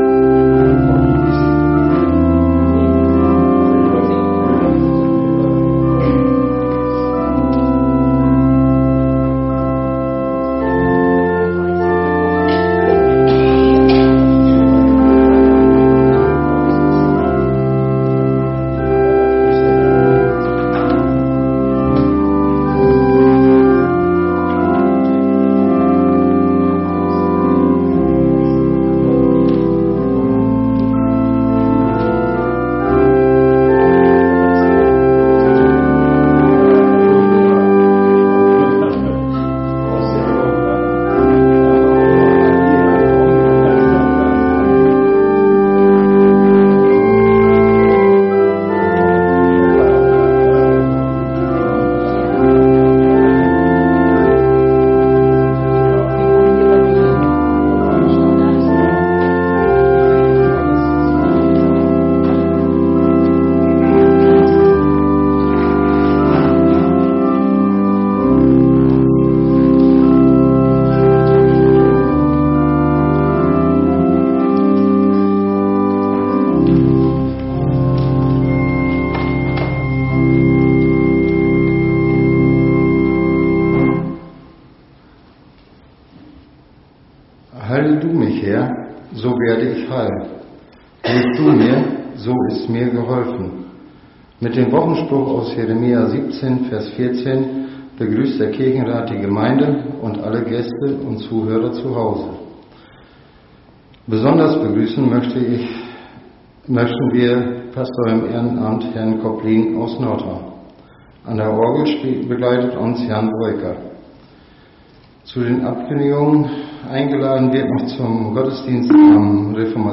Gottesdienst Sonntag 26.10.2025 | Evangelisch-altreformierte Kirchengemeinde Laar
Wir laden ein, folgende Lieder aus dem Evangelischen Gesangbuch und dem Liederheft mitzusingen: Lied 321, 3, Lied 324, 1 – 3 + 8 + 9, Lied 369, 1 – 3, Lied 369, 4 + 6 + 7, Lied 366, 1 – 4 + 6, Lied 240, 1 – 3